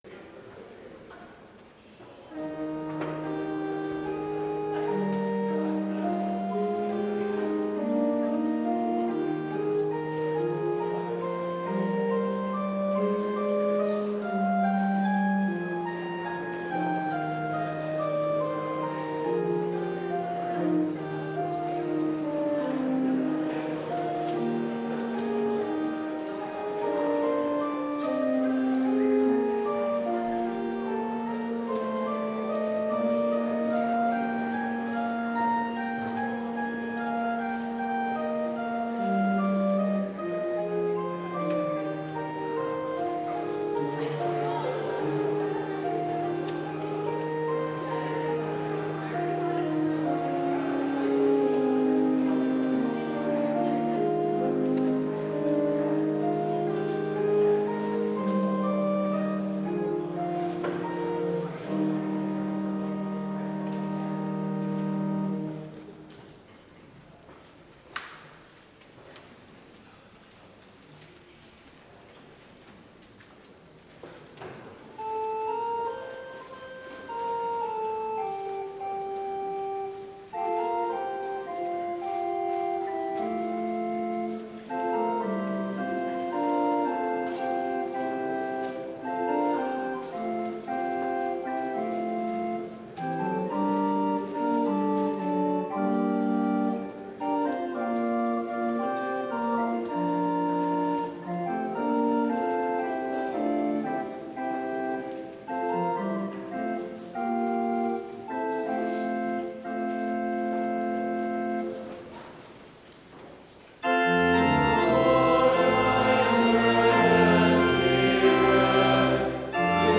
Complete service audio for Chapel - February 24, 2022